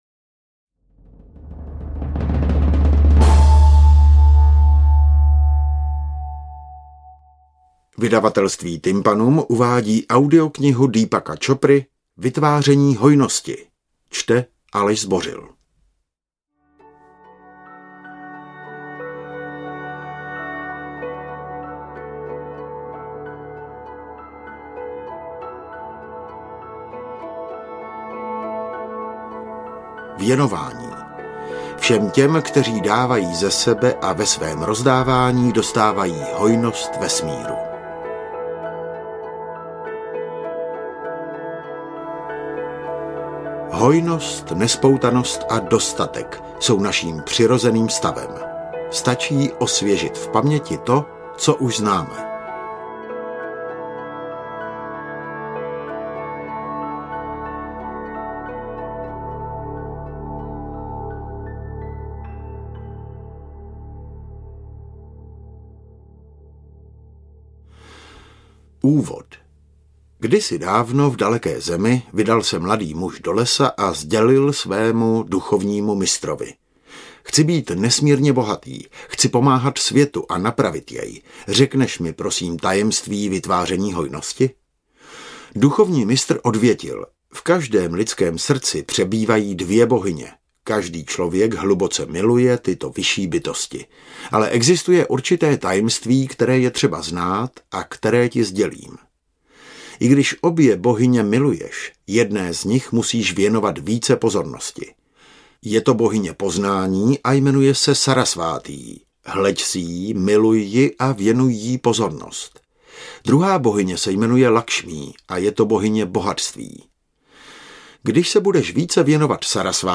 Vytváření hojnosti audiokniha
Ukázka z knihy